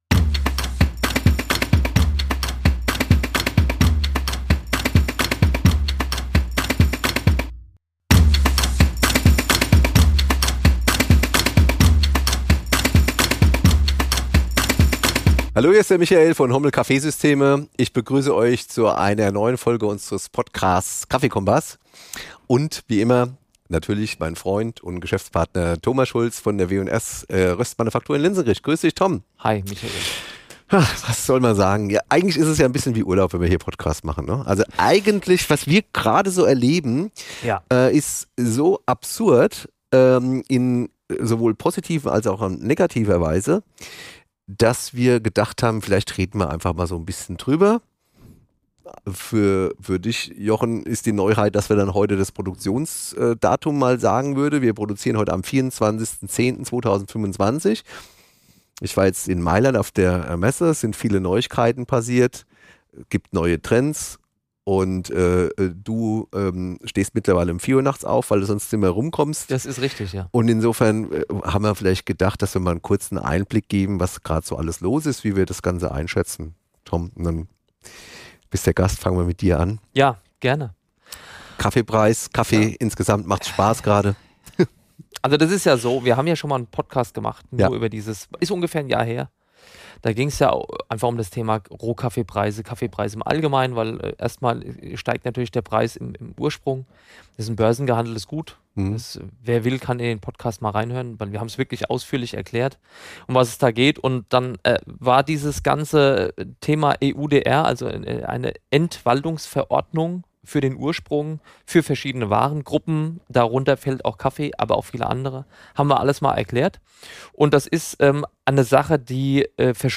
Beides sprüht dem Hörer der KaffeeKOMPASS Podcast-Reihe ab der ersten Sekunde entgegen, wenn zwei ausgewiesene Fachleute rund um das schwarze Gold fachsimpeln.